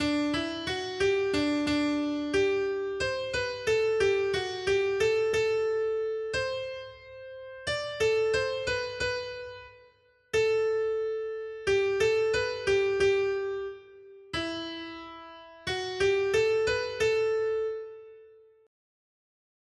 Noty Štítky, zpěvníky ol401.pdf responsoriální žalm Žaltář (Olejník) 401 Skrýt akordy R: Hospodin kraluje, je povznesen nad celou zemí. 1.